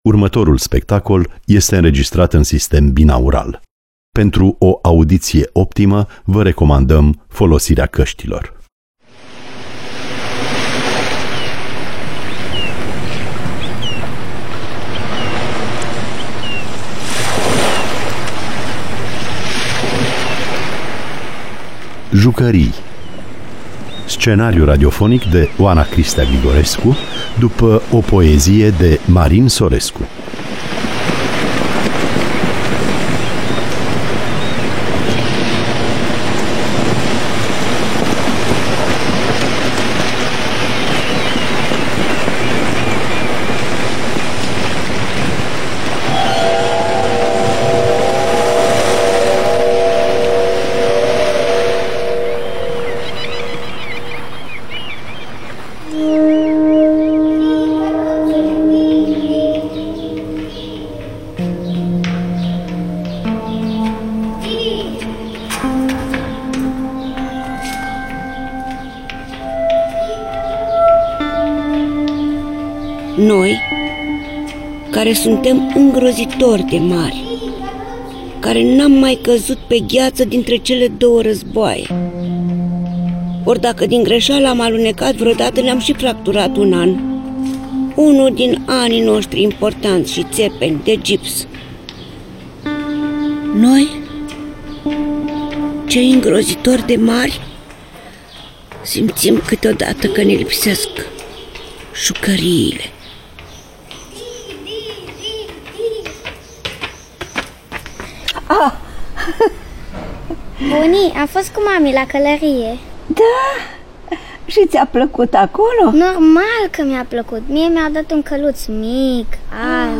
Dramatizare după poezia lui Marin Sorescu.